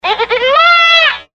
やぎの鳴き声